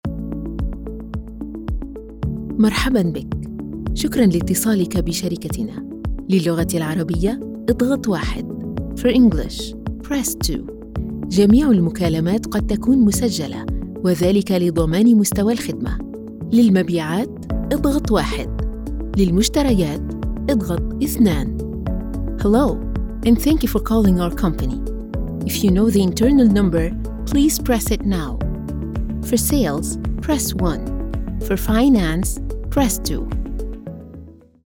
Female
Phone Greetings / On Hold
Bilingual-Ivr-Arabic + English
Words that describe my voice are Warm, Conversational.